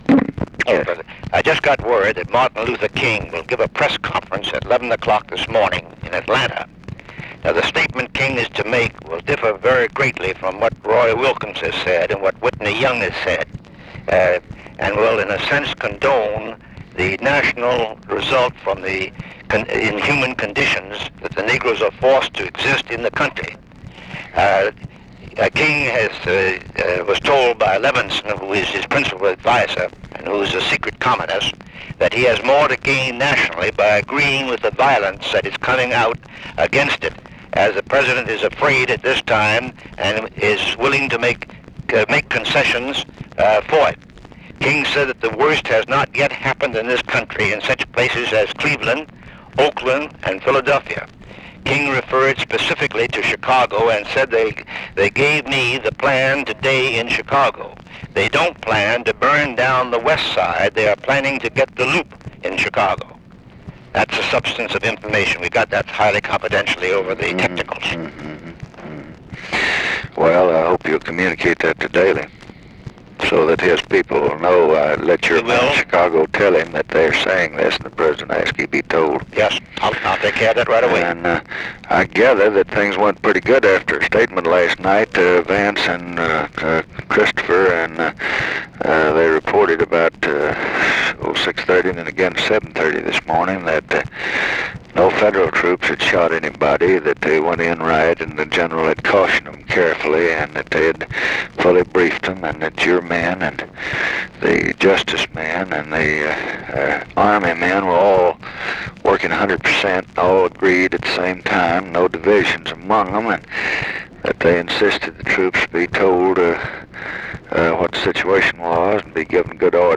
Conversation with J. EDGAR HOOVER, July 25, 1967
Secret White House Tapes